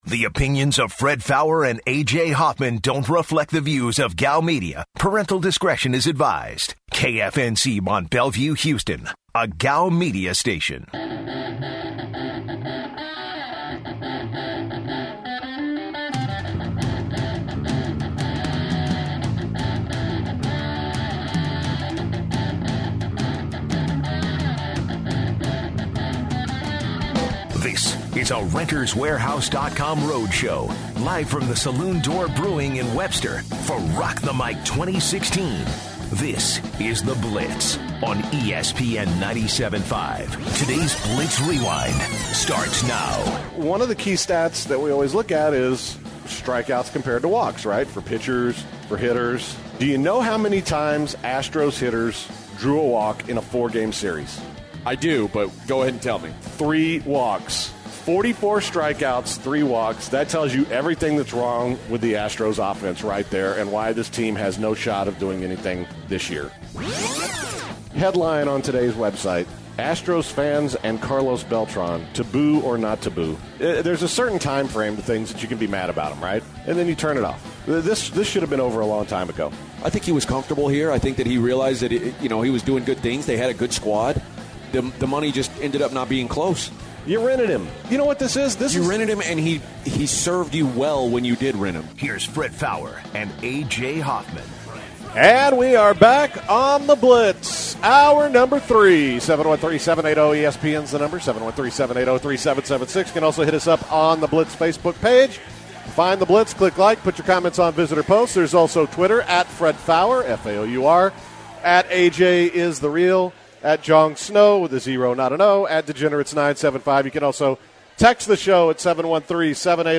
at the Saloon Door Brewing